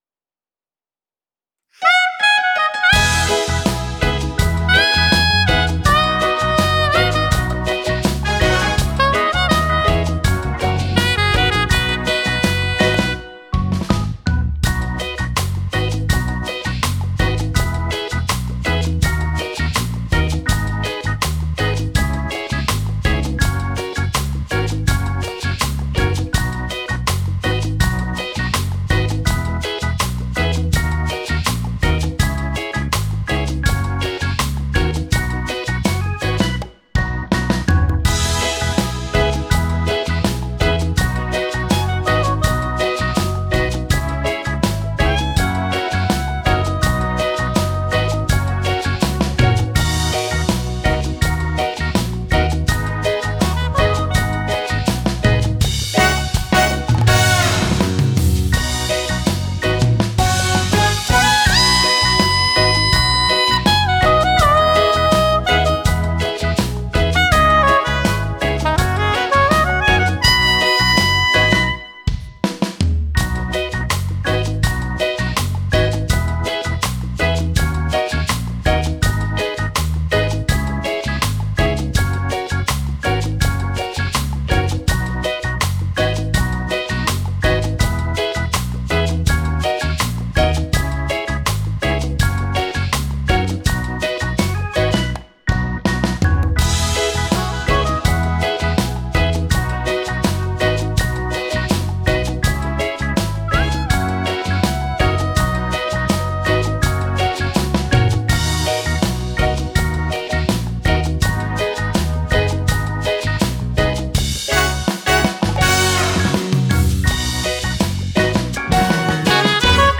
カラオケ